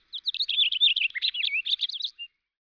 finch2.wav